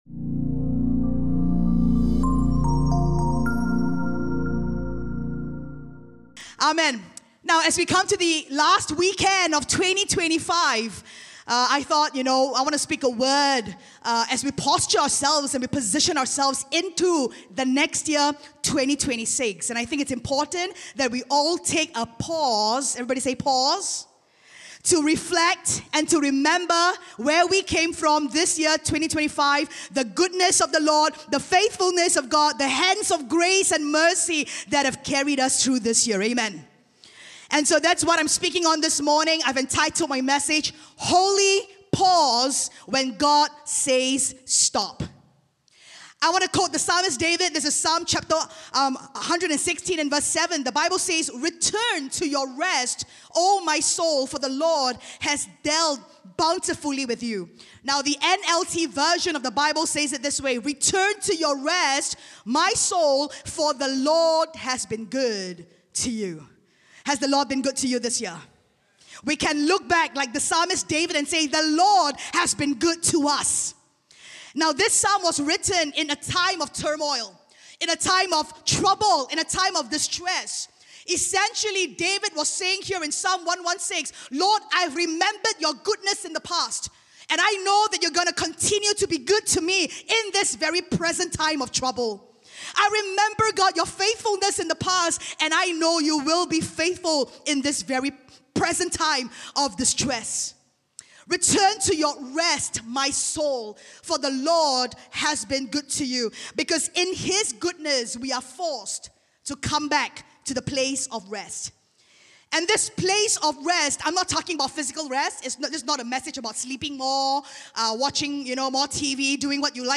Weekly audio sermons from Cornerstone Community Church in Singapore … continue reading 189 episodes # From Gods # Singapore # Cornerstone Community Church # Community Church # Society # Religion # Christianity